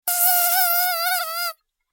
دانلود صدای حشره 18 از ساعد نیوز با لینک مستقیم و کیفیت بالا
جلوه های صوتی